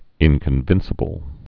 (ĭnkən-vĭnsə-bəl)